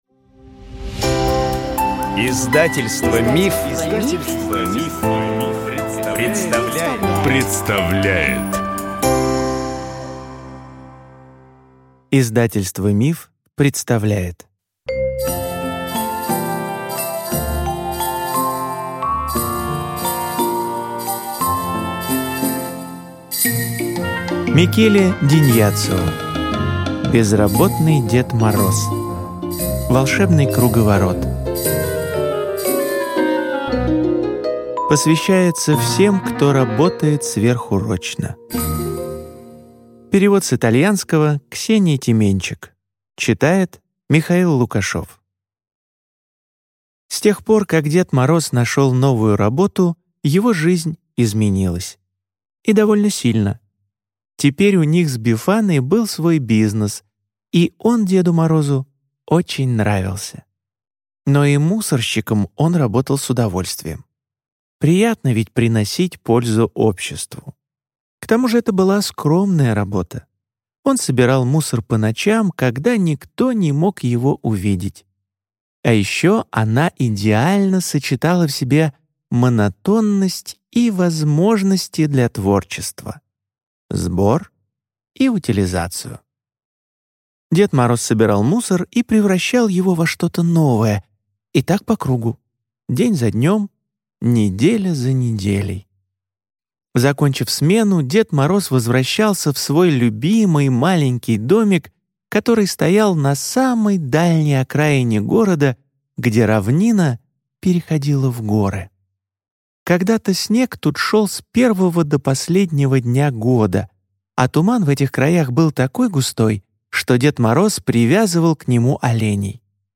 Аудиокнига Безработный Дед Мороз. Волшебный круговорот | Библиотека аудиокниг